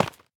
sounds / block / tuff / break1.ogg
break1.ogg